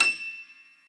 b_piano1_v100l32-3o8e.ogg